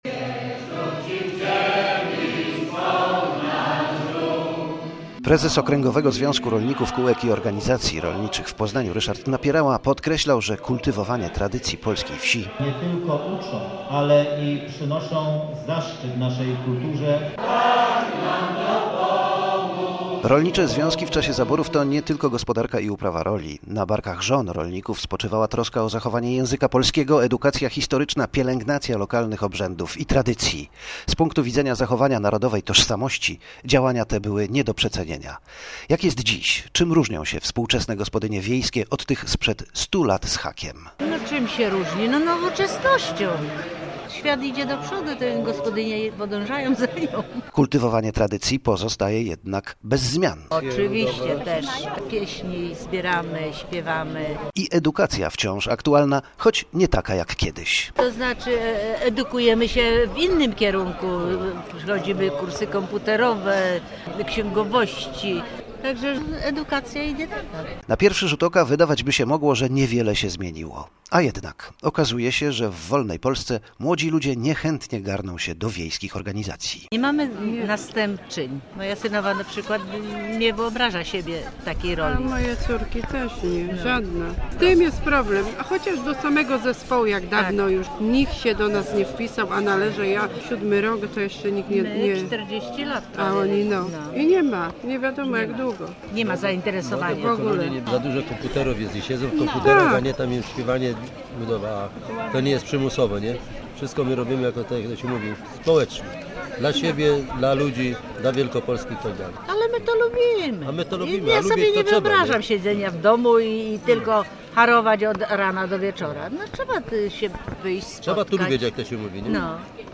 Wojewódzką uroczystość z tej okazji zorganizowano w weekend w Sielinku koło Opalenicy, w tamtejszym Ośrodku Doradztwa Rolniczego.